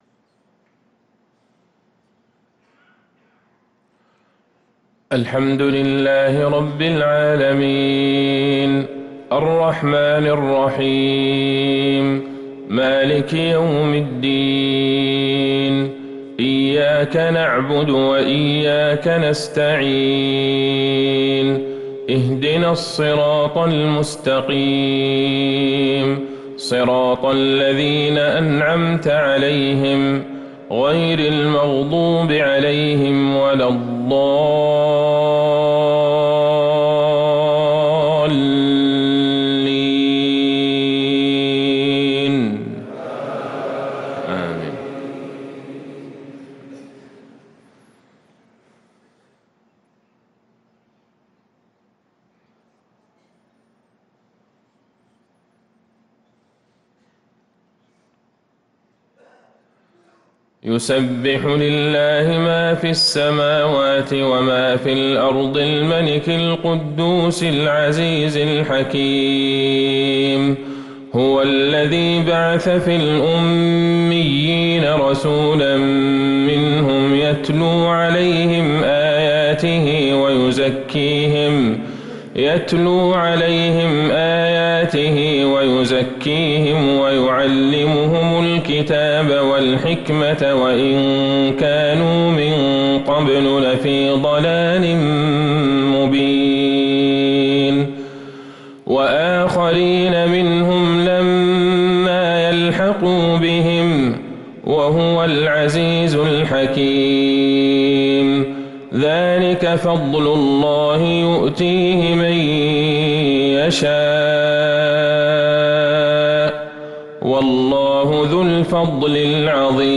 صلاة الفجر للقارئ عبدالله البعيجان 20 ذو القعدة 1444 هـ
تِلَاوَات الْحَرَمَيْن .